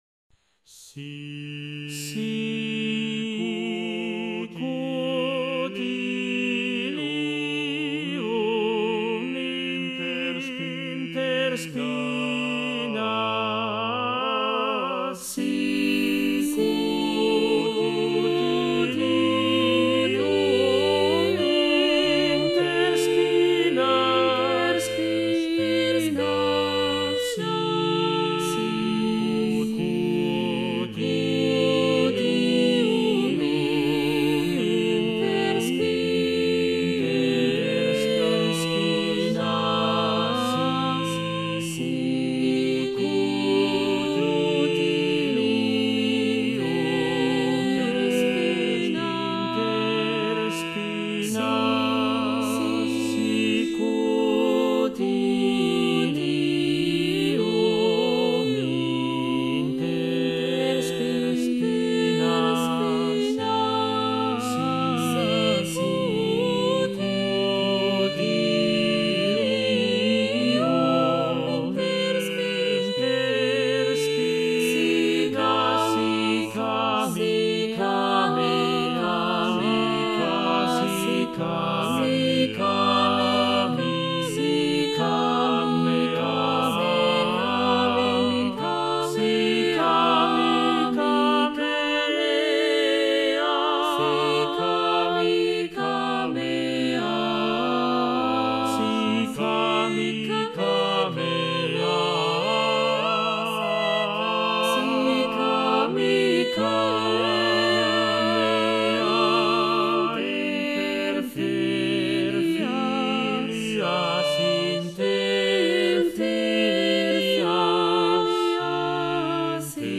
Small renaissance motet wich i sadly wrote the text wrong :(
I made this very small renaissance motet for 4 voices, Cantus, Altus, Tenor and Bassus.